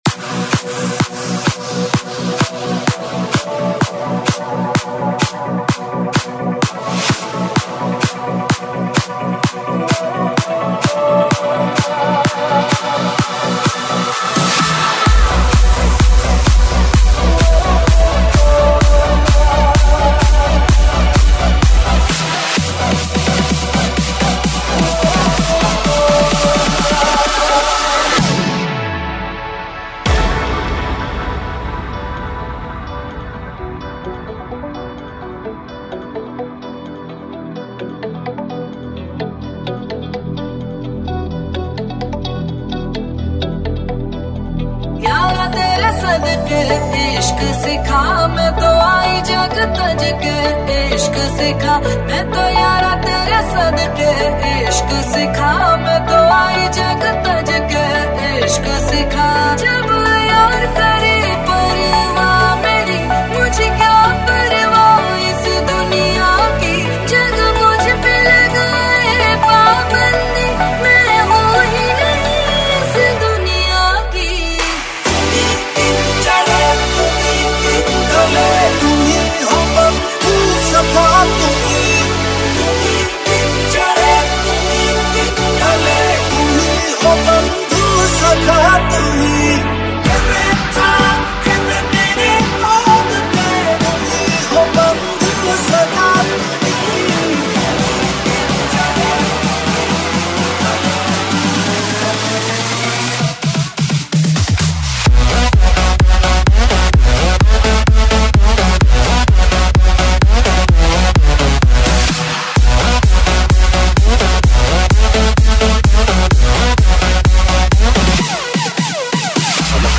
DJ Remix Mp3 Songs
Mashup Mp3 Song